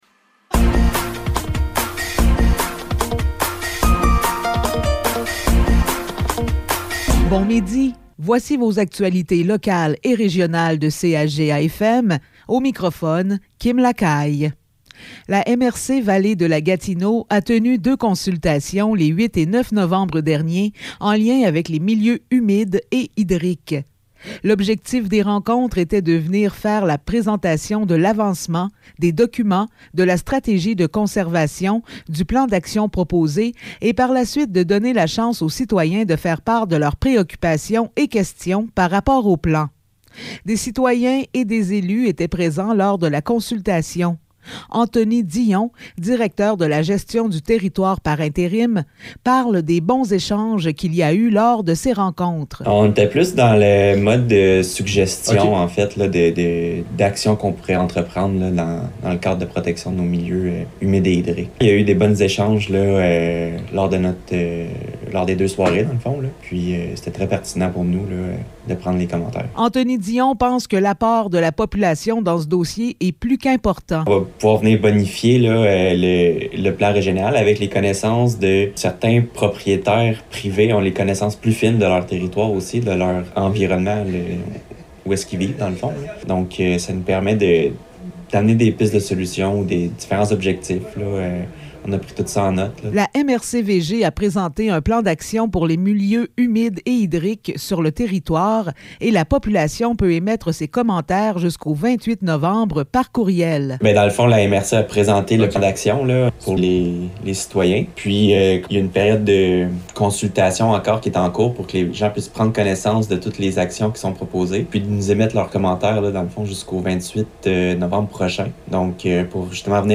Nouvelles locales - 11 novembre 2022 - 12 h